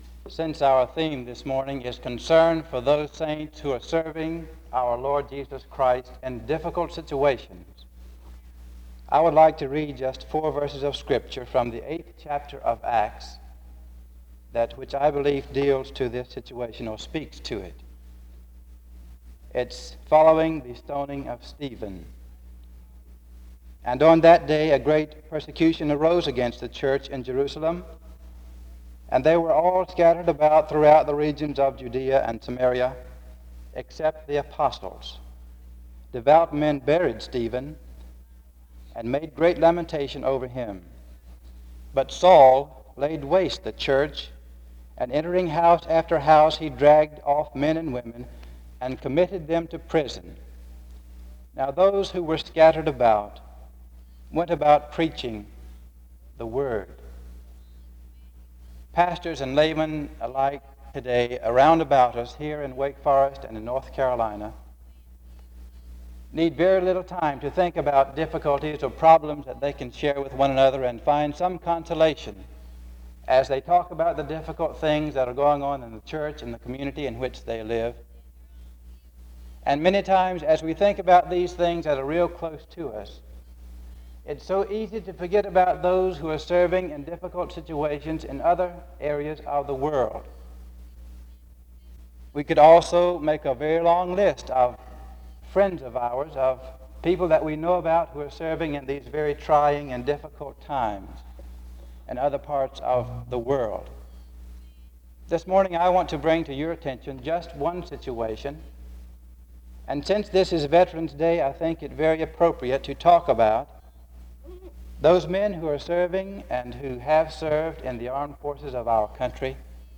• Wake Forest (N.C.)